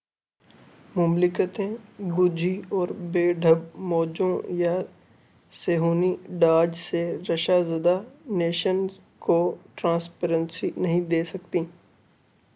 deepfake_detection_dataset_urdu